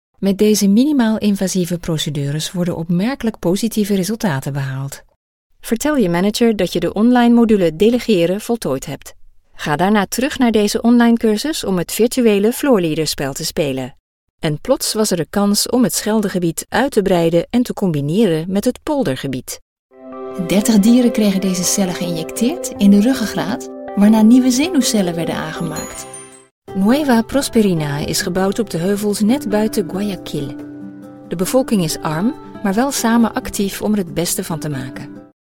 Dutch French and neutral English voice-over talent with professional home studio.
Sprechprobe: eLearning (Muttersprache):
European voiceover artist with an international sound: warm, reassuring, rich, engaging, classy.